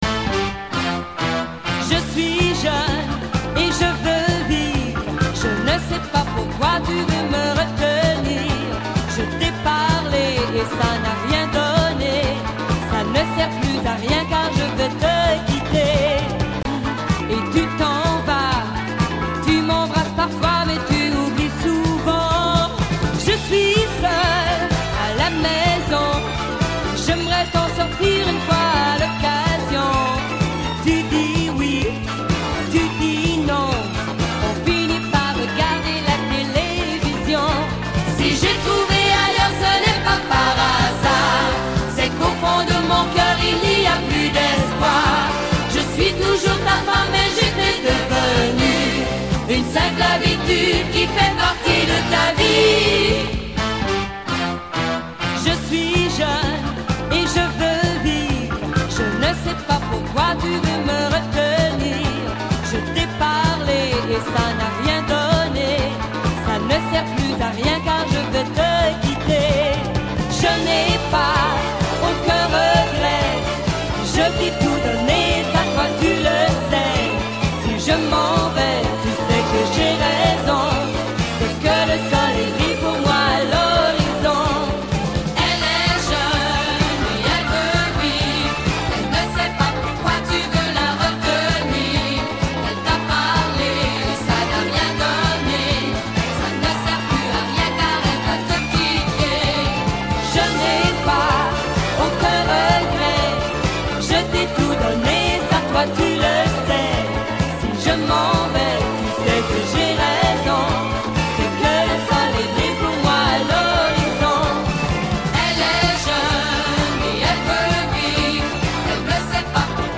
Вот она с радио.Кусочек.